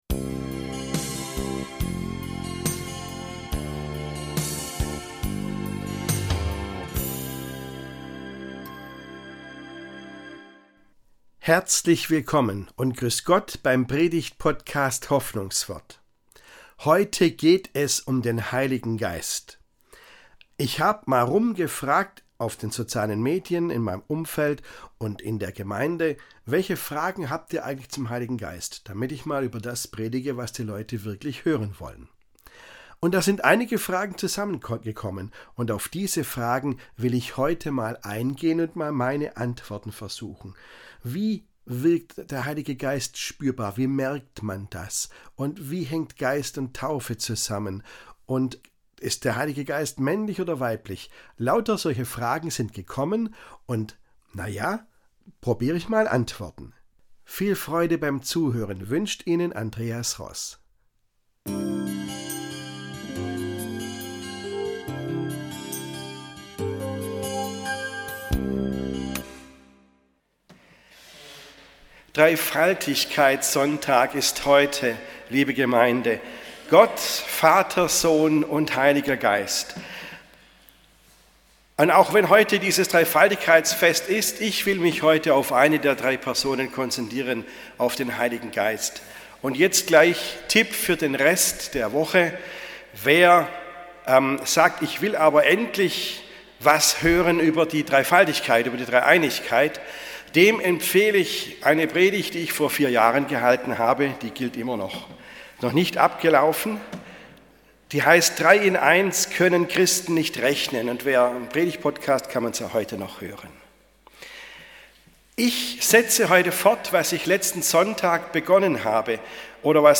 In dieser Predigt über den Heiligen Geist versucht er sich an Antworten.